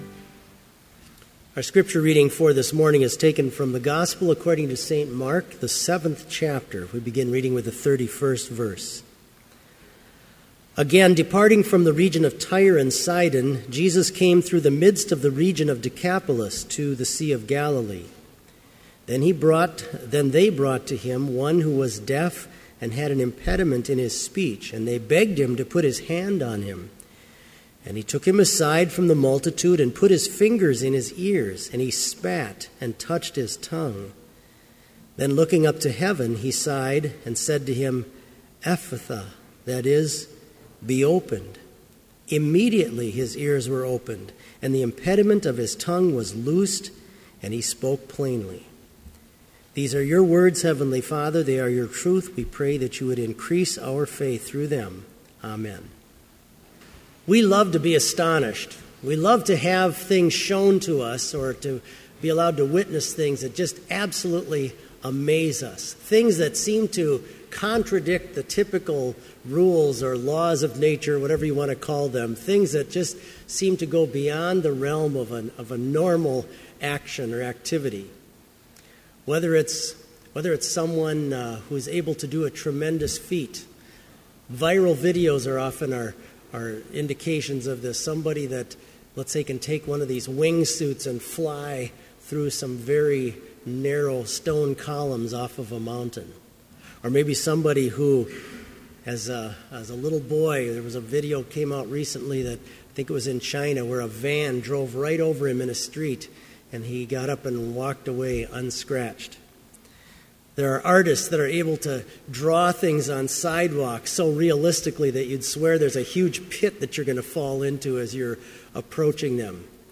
Complete service audio for Chapel - September 8, 2014